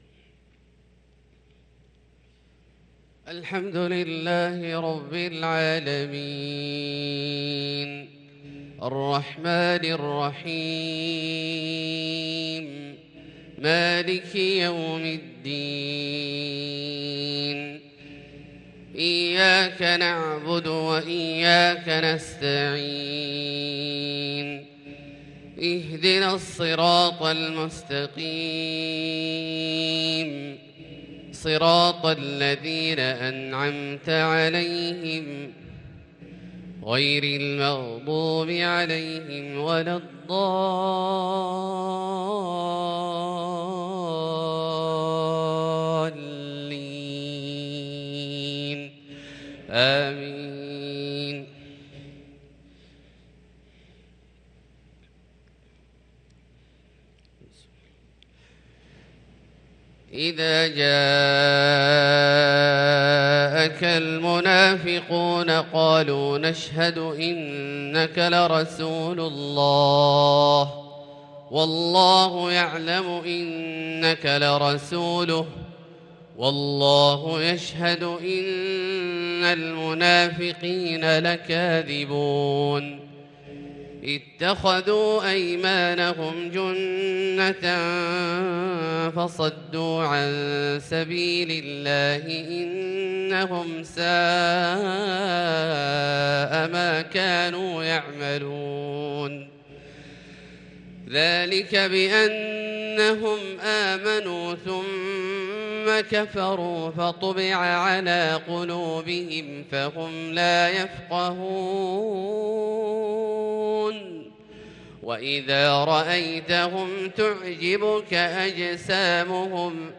صلاة الفجر للقارئ عبدالله الجهني 16 ذو القعدة 1443 هـ
تِلَاوَات الْحَرَمَيْن .